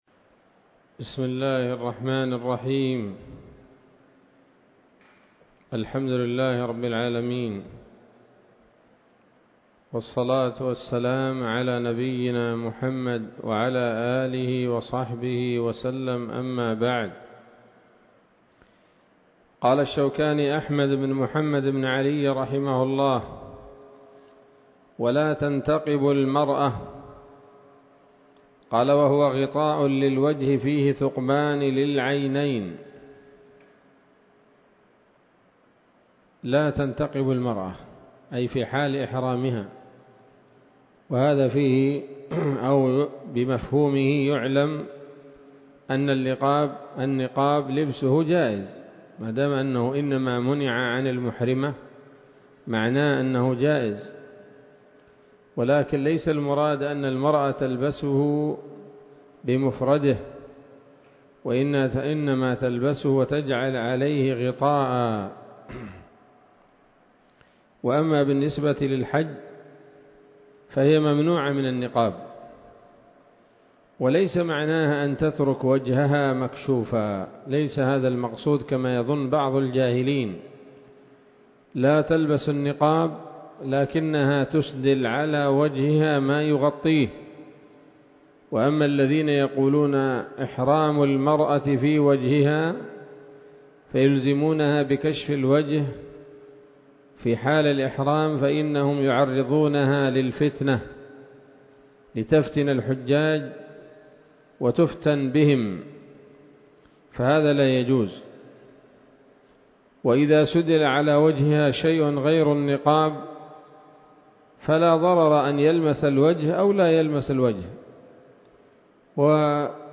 الدرس السابع من كتاب الحج من السموط الذهبية الحاوية للدرر البهية